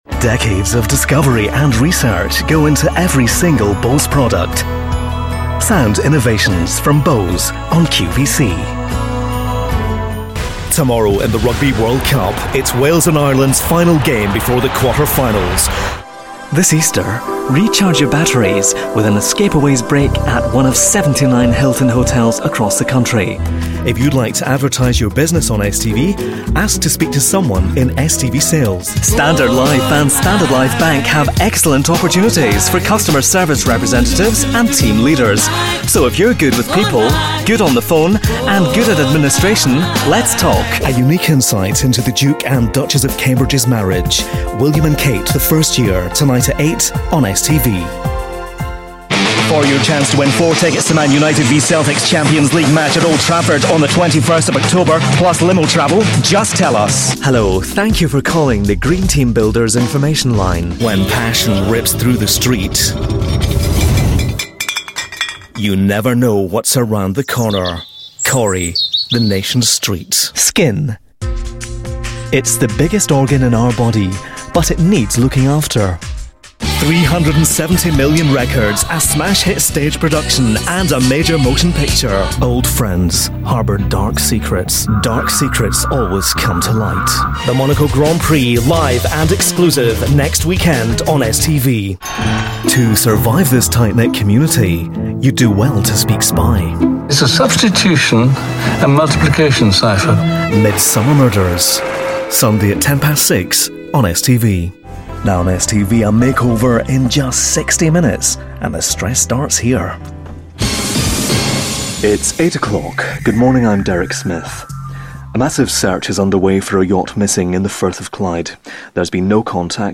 Mellow friendly characterful.
schottisch
Sprechprobe: Werbung (Muttersprache):
Authentic, Conversational, Convincing, Compelling, Natural,Positive,Punchy.
Showreel Montage 2012.mp3